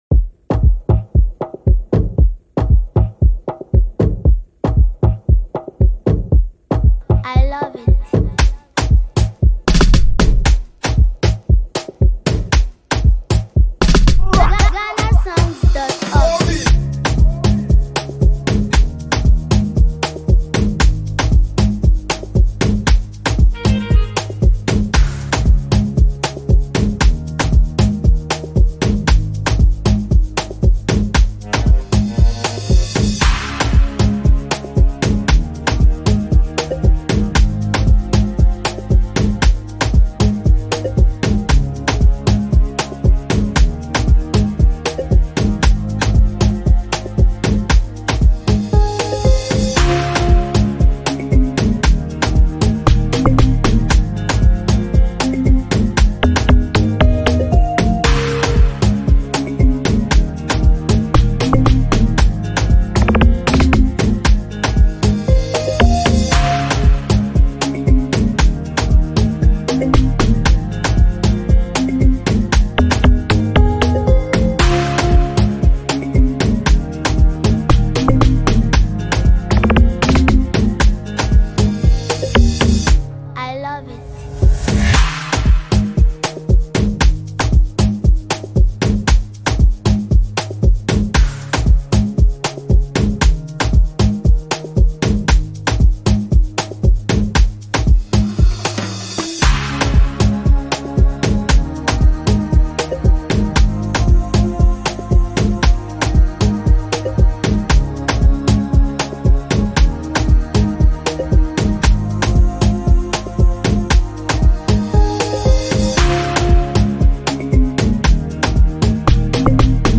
Free Beat INSTRUMENTAL
instrumental remake